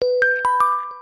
уведомление